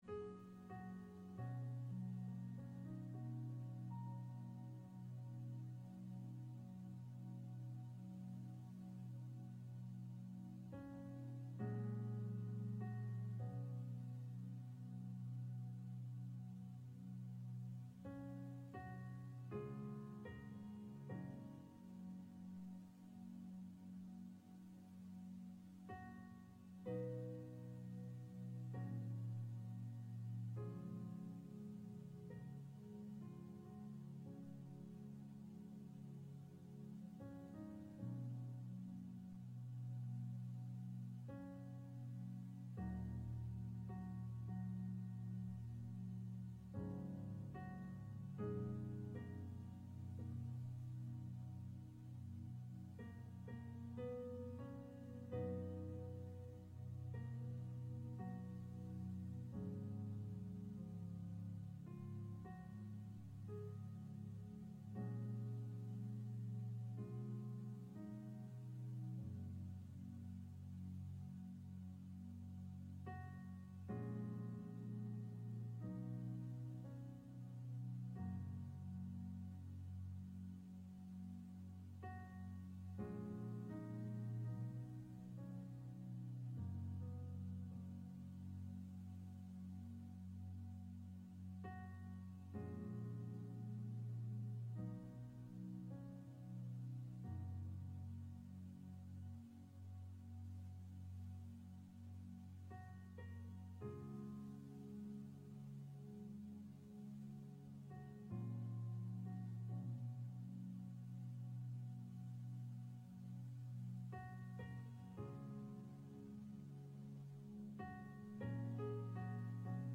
Service Morning Worship
September 20 Worship Audio – Full Service September 20 Sermon Audio Bible References 1 Kings 12:25 - 33